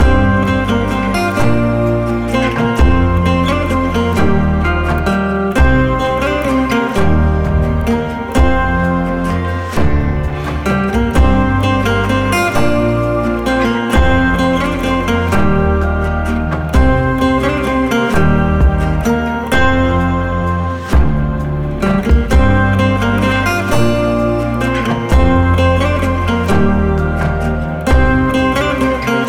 Projet après le mixage